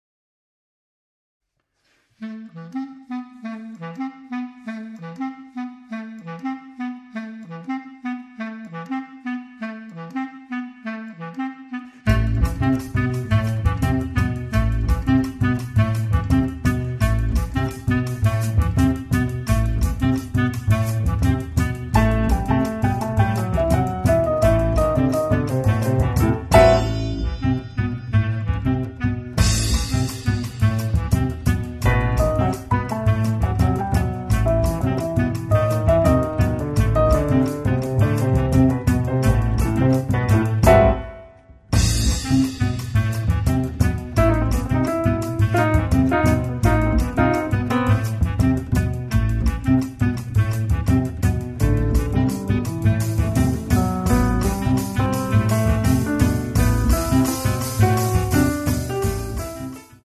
sax soprano, tenore, clarinetto
pianoforte
contrabbasso
batteria